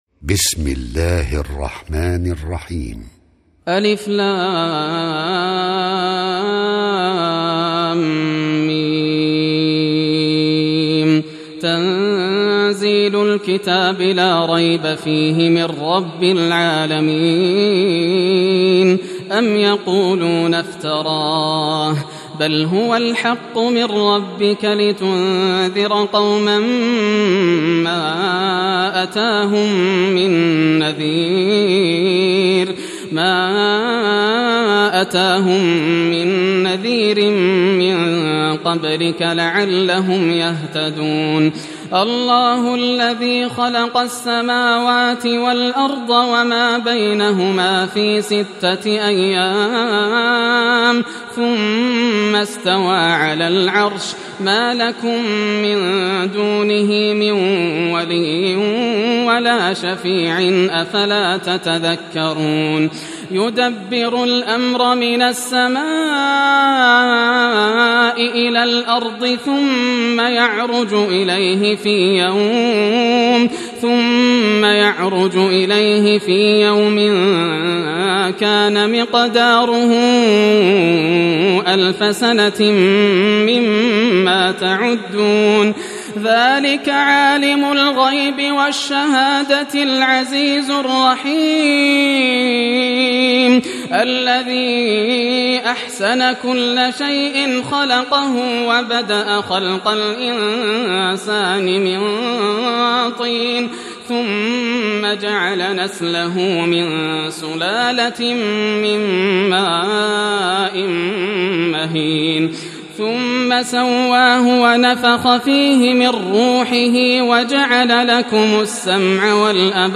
سورة السجدة > المصحف المرتل للشيخ ياسر الدوسري > المصحف - تلاوات الحرمين